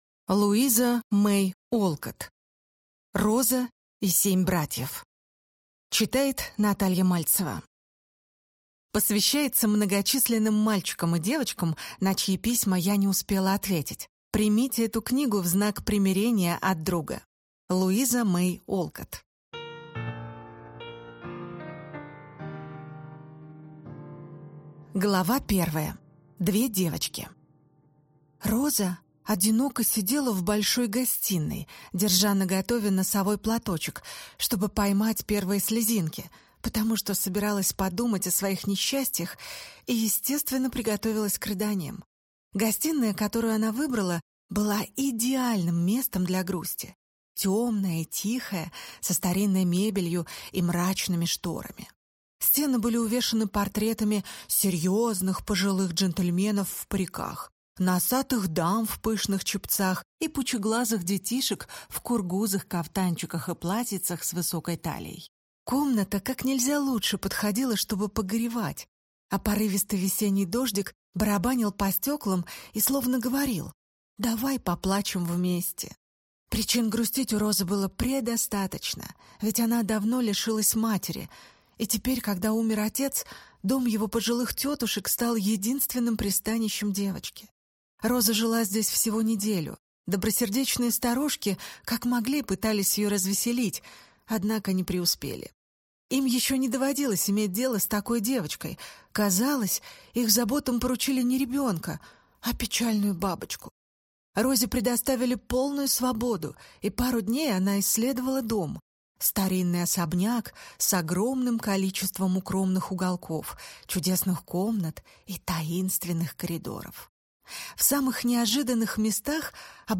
Аудиокнига Роза и семь братьев | Библиотека аудиокниг